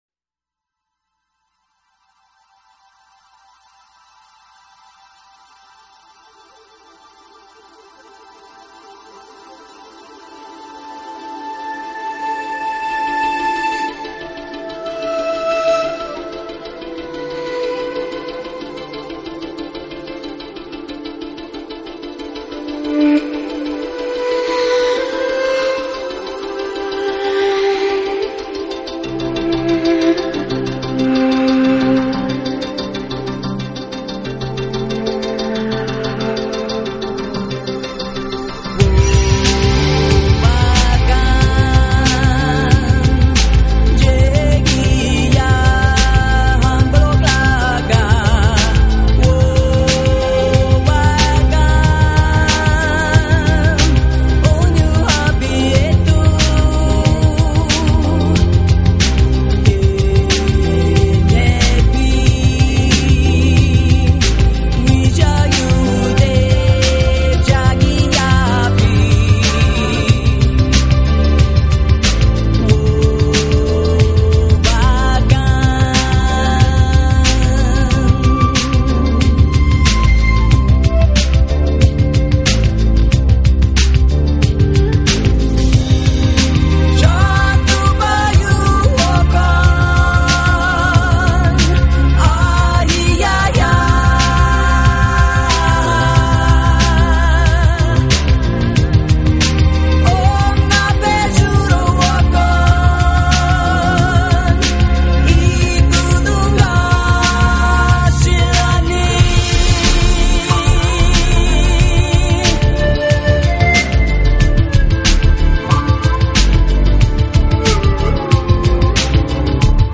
是领唱上，都加重了女声的成分，因而整体风格都显得更柔和、更温暖。